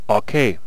archer_ack6.wav